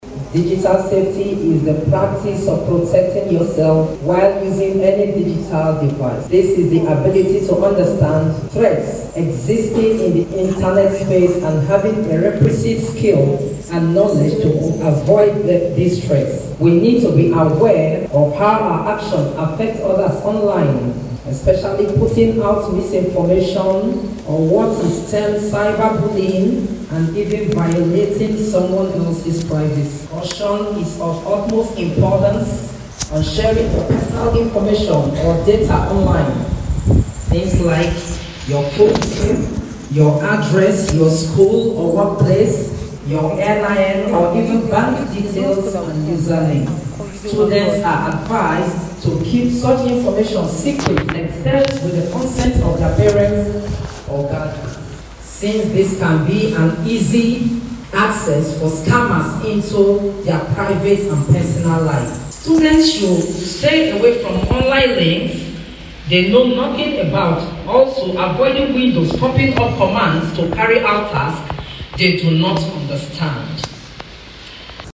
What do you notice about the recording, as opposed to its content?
This was made known during a Digital Safety Conference for Secondary Schools organised by SieDi Hub, a non-governmental organisation, in collaboration with National Orientation Agency (NOA) and other development partners in Umuahia.